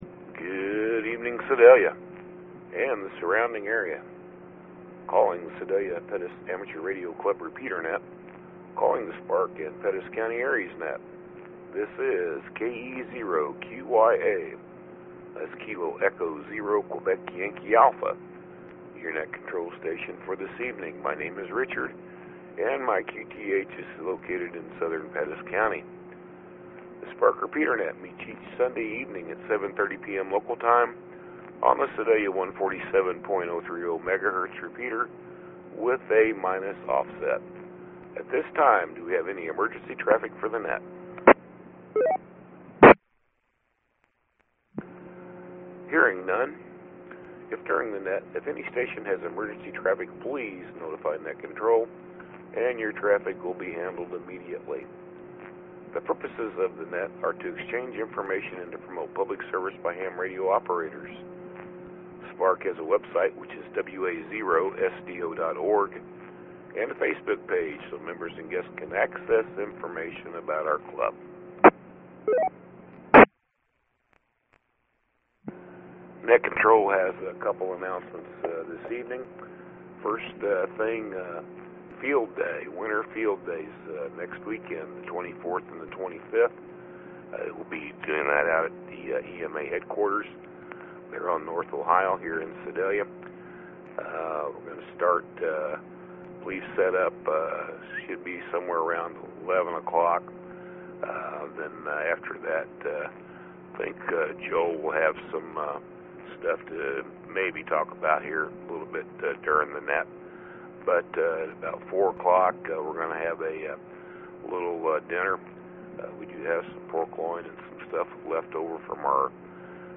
SPARK 2M Net 21 Apr 2024